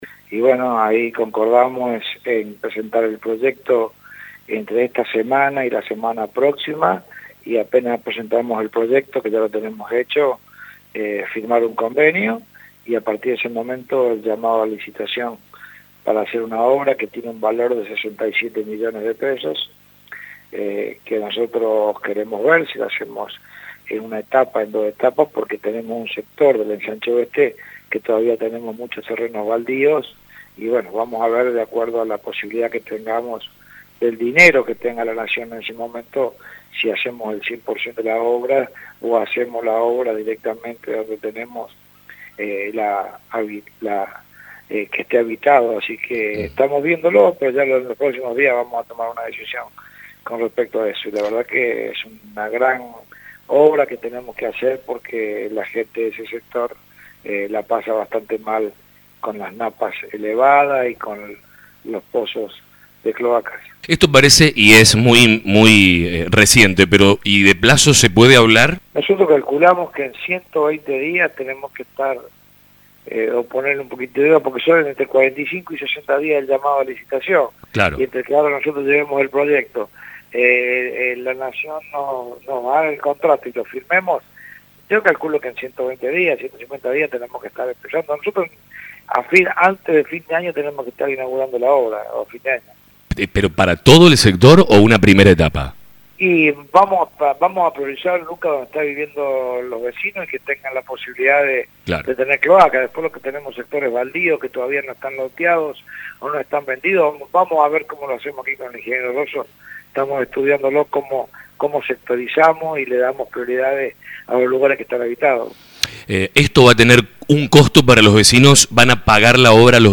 En conversaciones con La Mañana, el intendente Fabián Francioni dijo para fin de año la obra debería estar terminada.